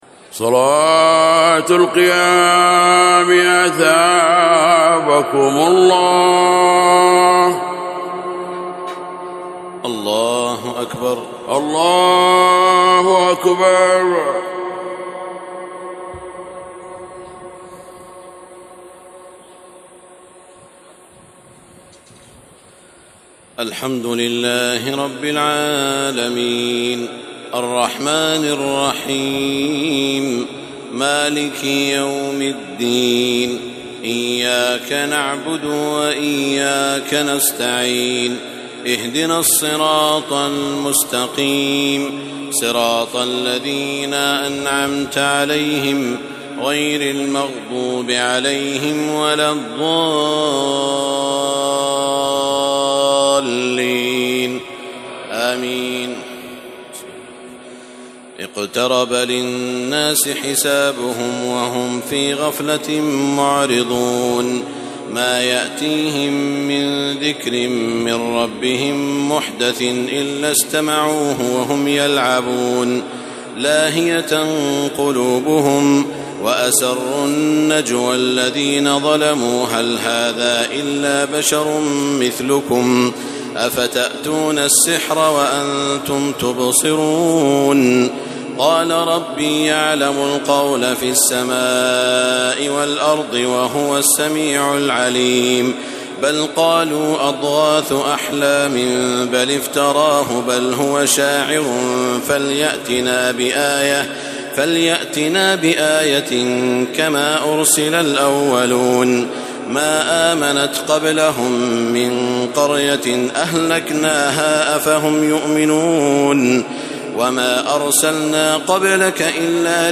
تراويح الليلة السادسة عشر رمضان 1432هـ سورة الأنبياء كاملة Taraweeh 16 st night Ramadan 1432H from Surah Al-Anbiyaa > تراويح الحرم المكي عام 1432 🕋 > التراويح - تلاوات الحرمين